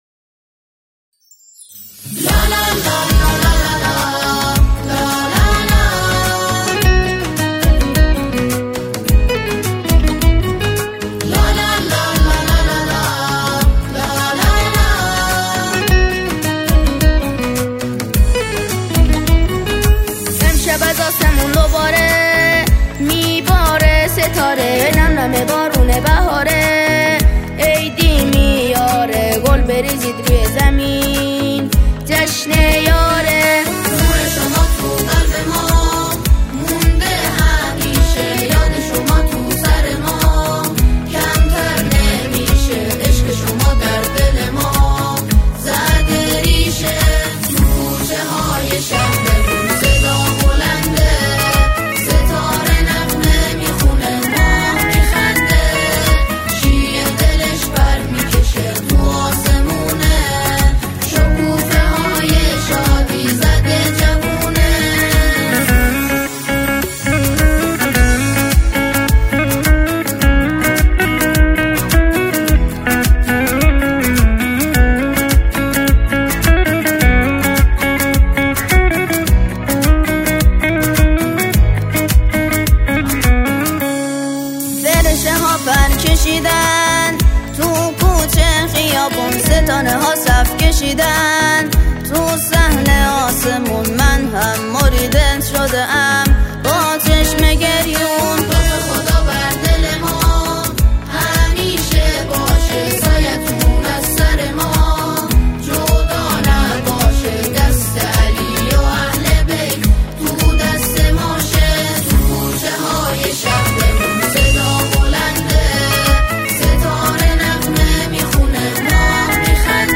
سرودهای اعیاد اسلامی